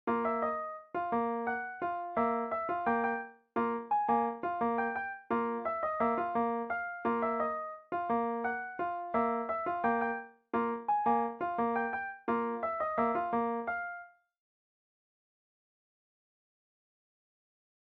This is about speed change. The beat gets faster.